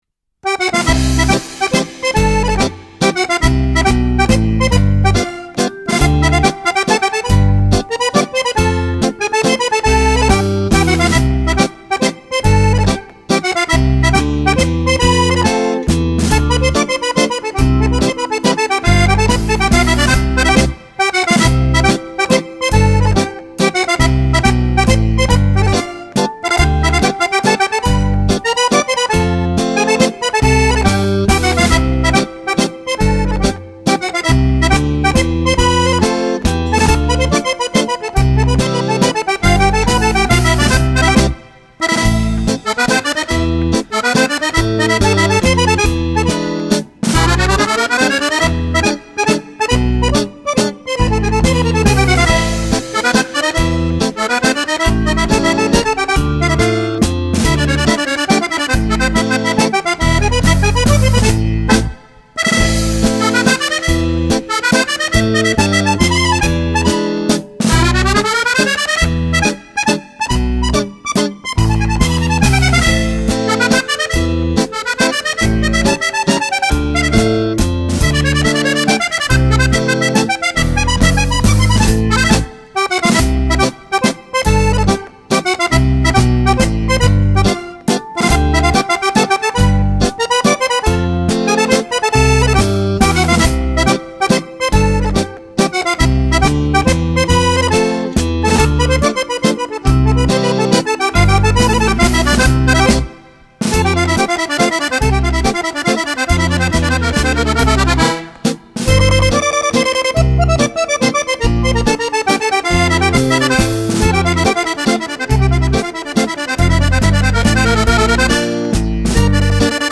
Genere: Mazurka